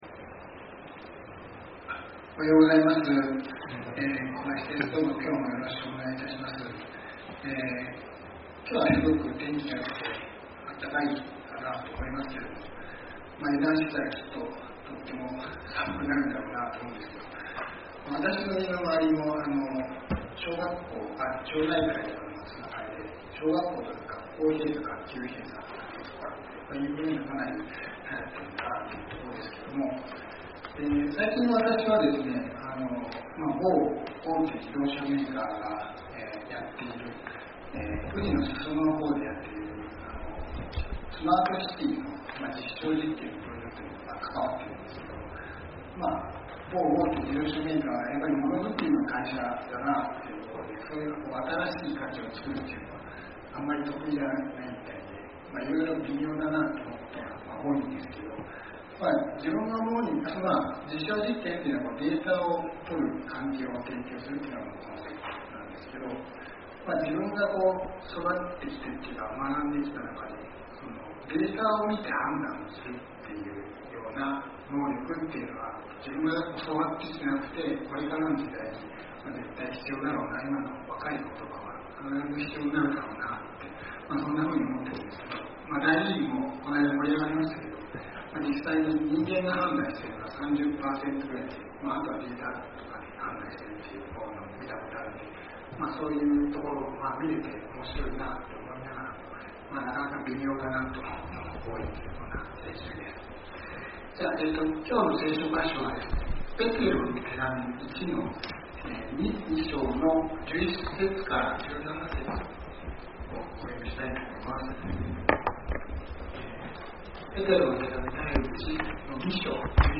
先週，東京集会で行わ れた礼拝で録音された建徳です。